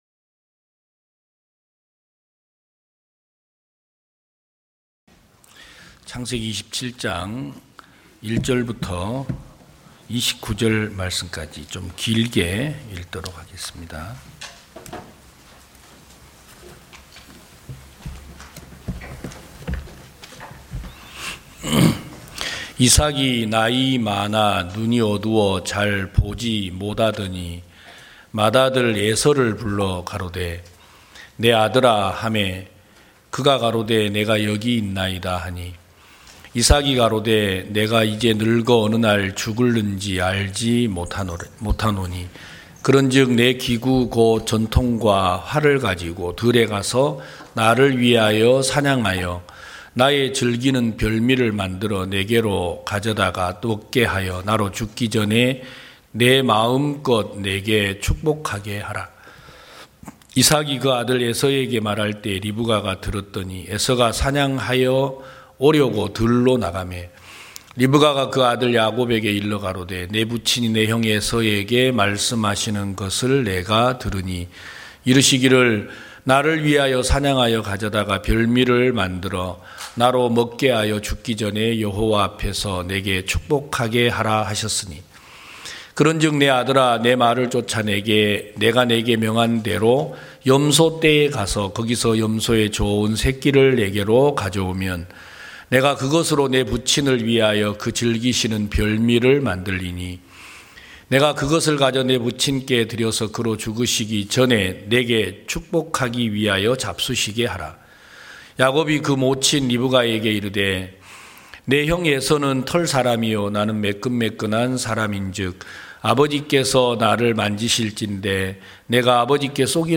2023년 1월 8일 기쁜소식부산대연교회 주일오전예배
성도들이 모두 교회에 모여 말씀을 듣는 주일 예배의 설교는, 한 주간 우리 마음을 채웠던 생각을 내려두고 하나님의 말씀으로 가득 채우는 시간입니다.